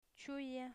Phonological Representation 'ʧuja